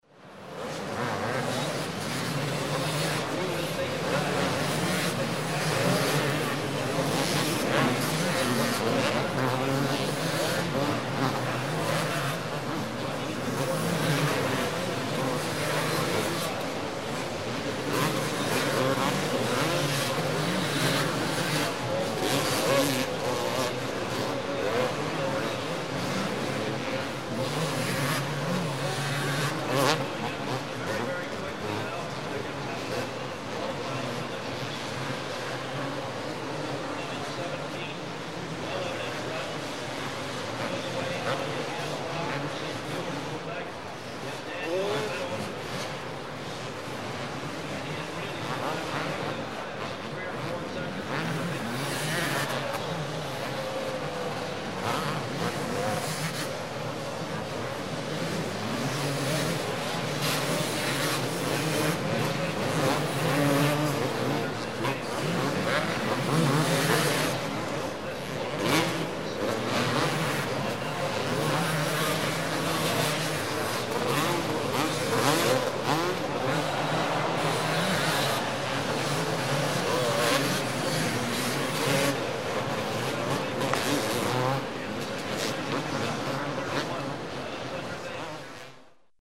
Звуки мотокросса